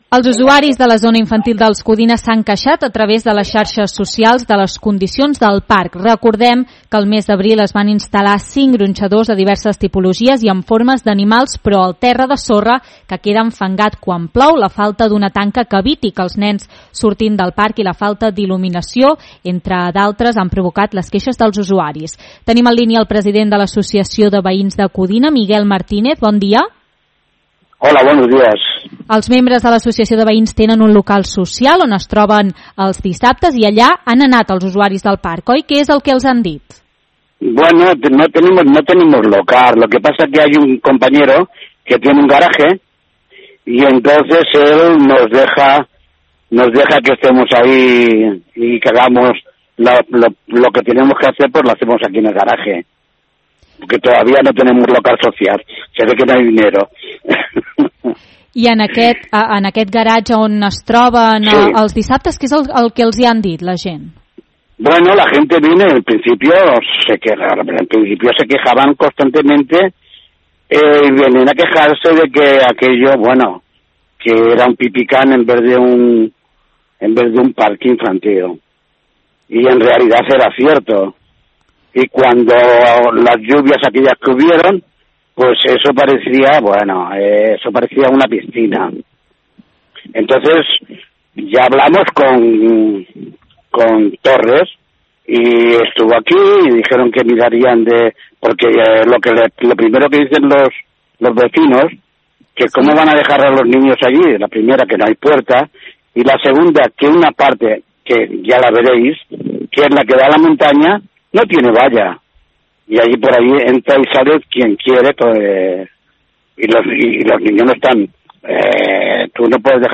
en una entrevista a l’Info Migdia.